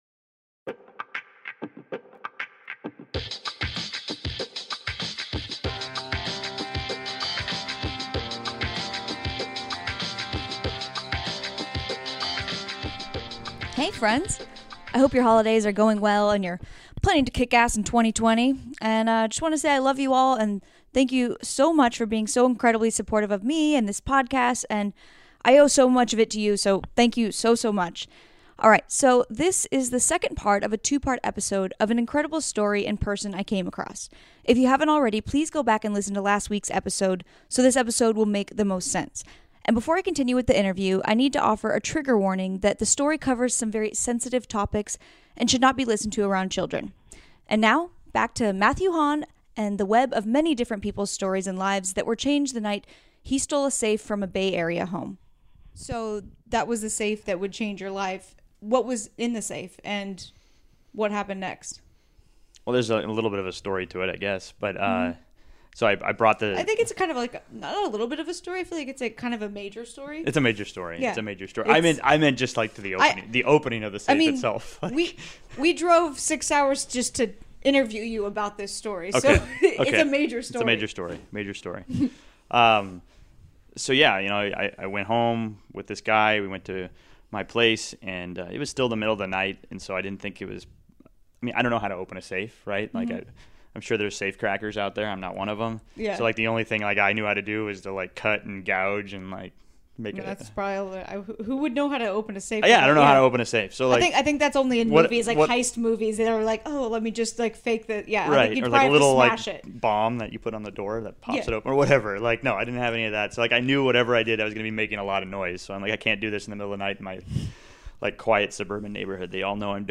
Before I continue with the interview, I need to offer a trigger warning that the story covers some very sensitive topics and should not be listened to around children.